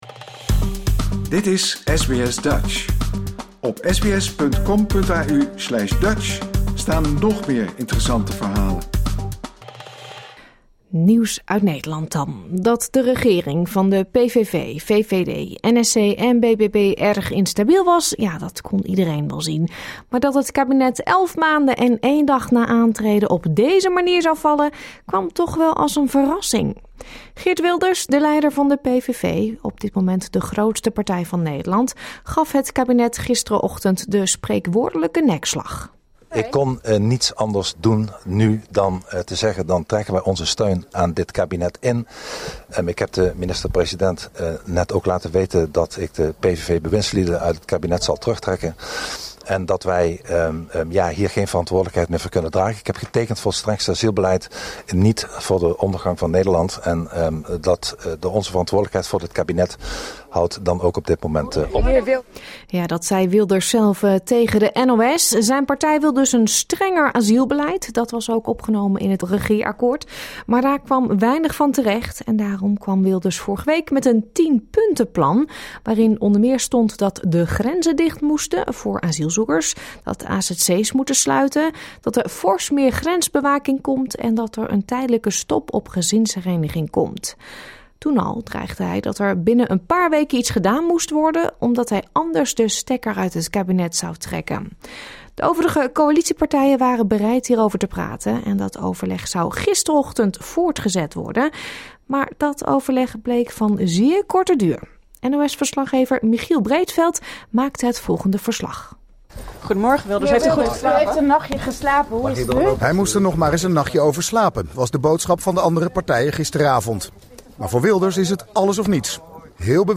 Je hoort de reacties van Geert Wilders van de PVV, coalitiepartijen VVD, NSC en BBB, premier Dick Schoof en de oppositie.